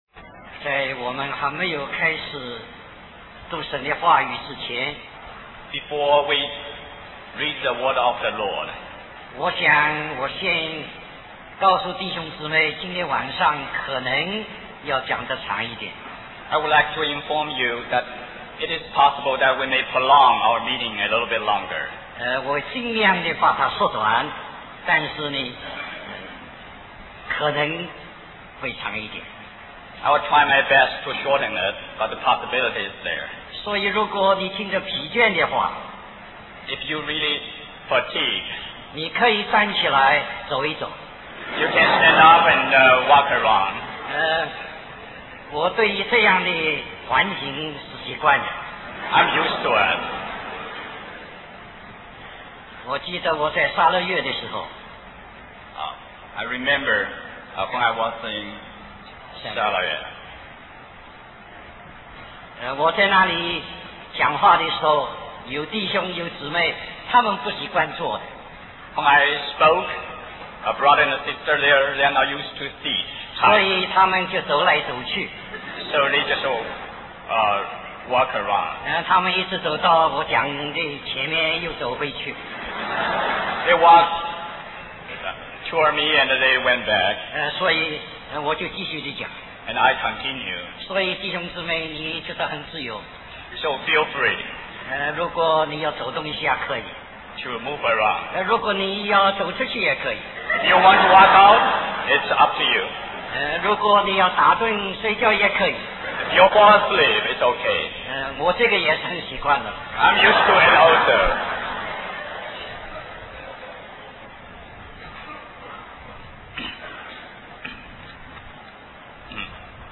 1989 Special Conference For Service, Hong Kong Stream or download mp3 Summary This message is continued here .